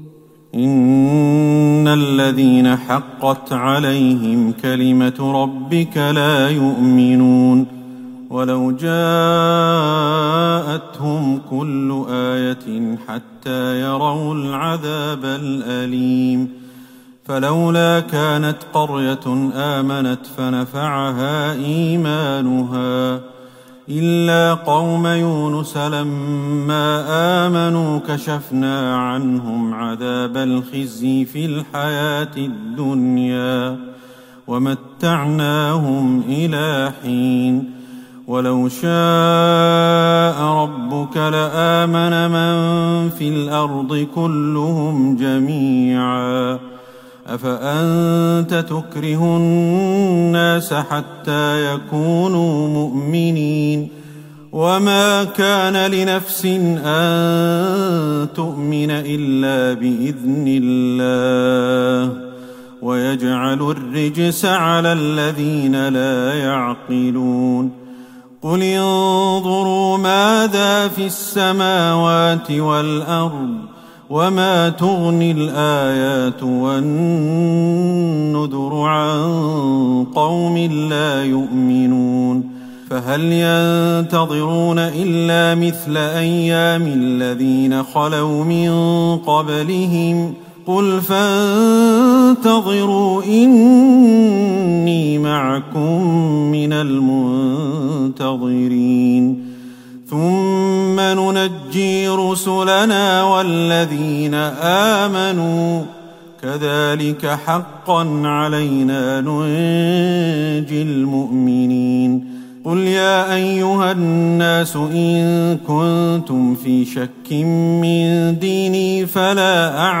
ليلة ١٥ رمضان ١٤٤١هـ من سورة يونس { ٩٦-١٠٩} وهود { ١-٣٥ } > تراويح الحرم النبوي عام 1441 🕌 > التراويح - تلاوات الحرمين